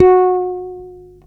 44-F#4.wav